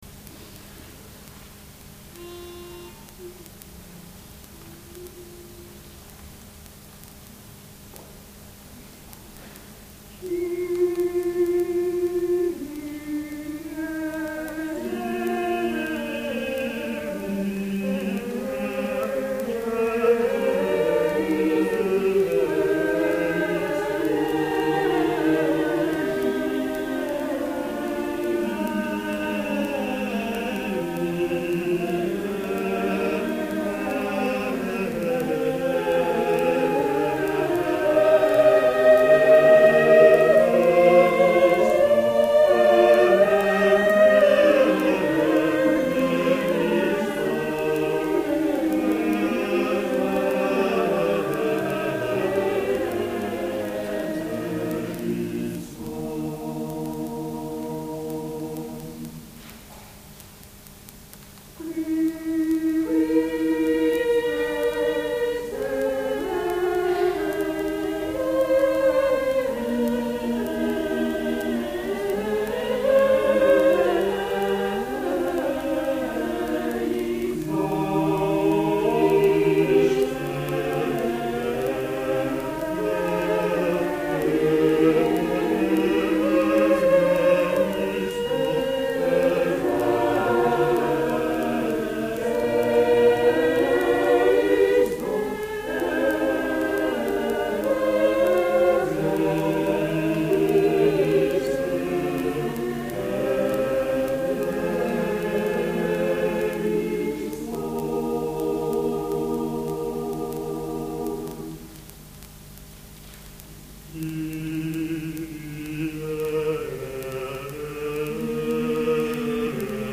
第32回野田市合唱祭
野田市文化会館